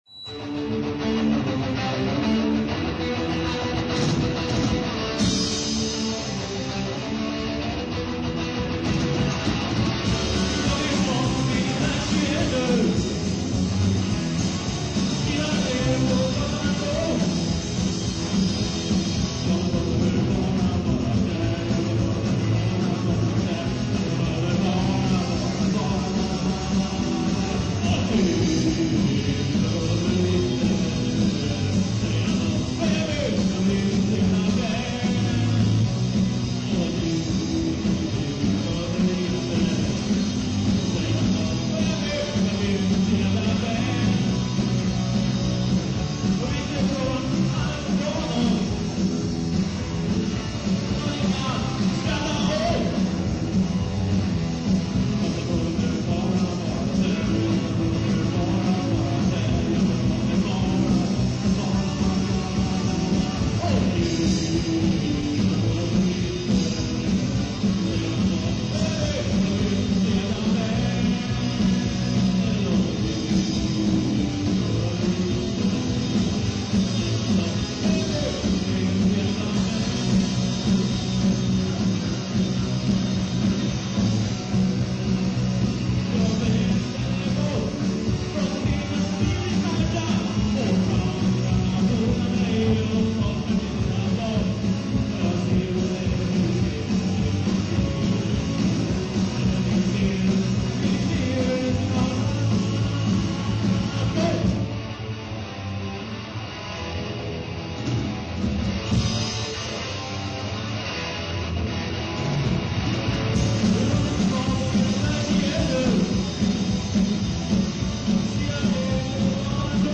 Guitar
Drums
Bass
Voice